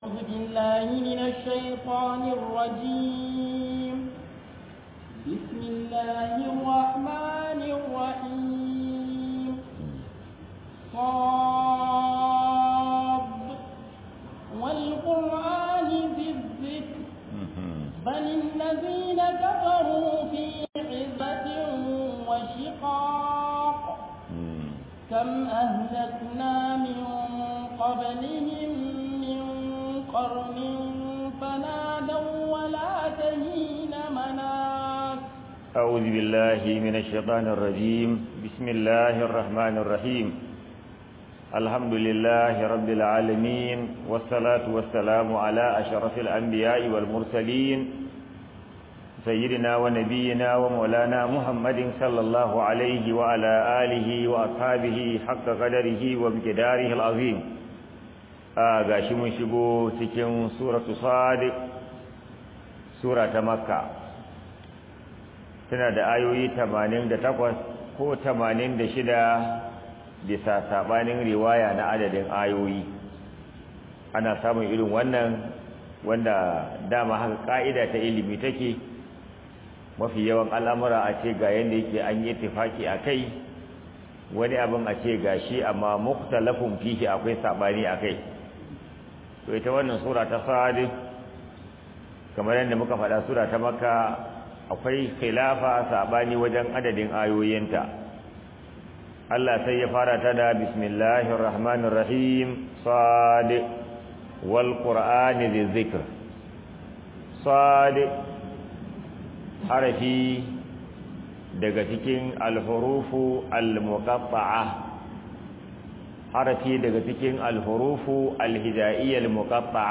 007 TAFSEER MASALLACI.mp3